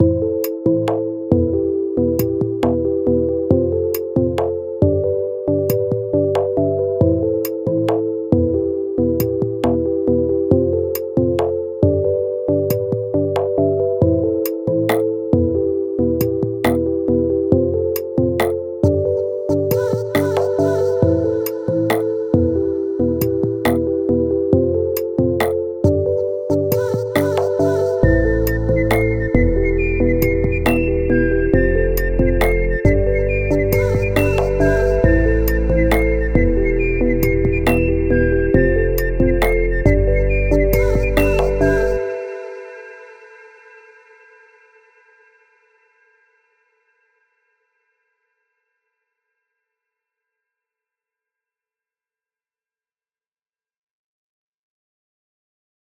But I did record a whistle! And it plays a little melody.